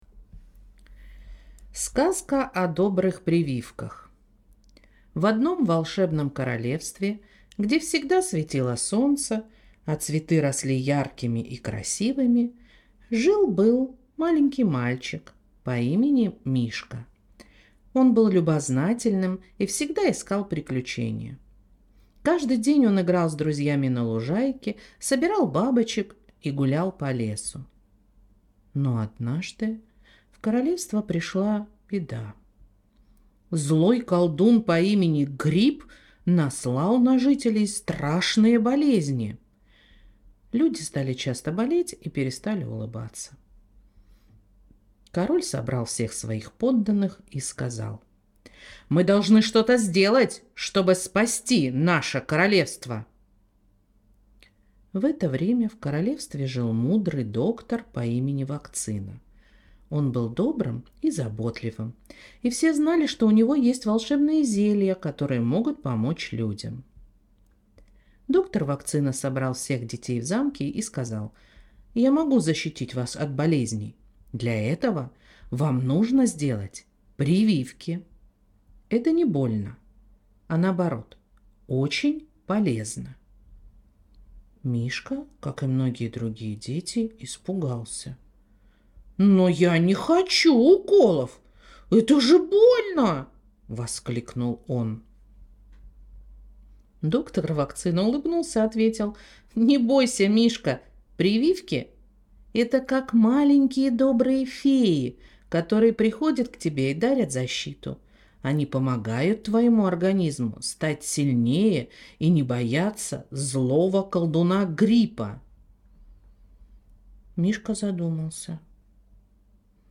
Аудиосказки